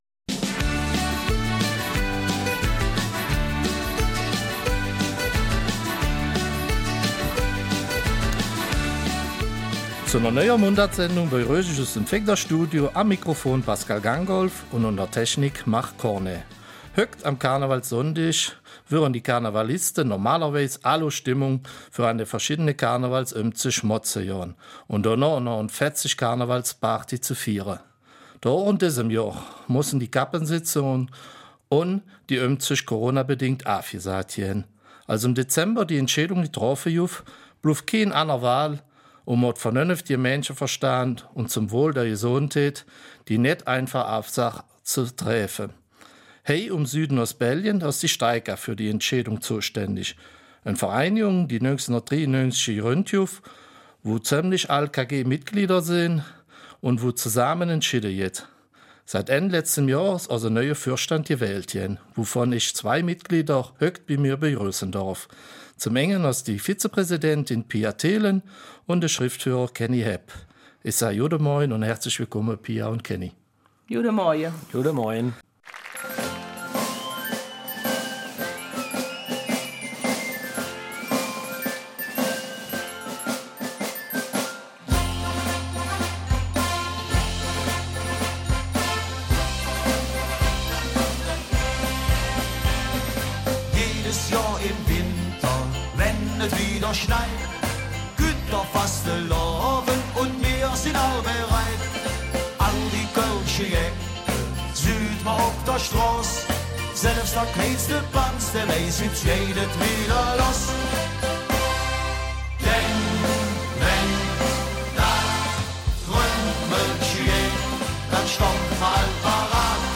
Eifeler Mundart: Neuer Vorstand der Steika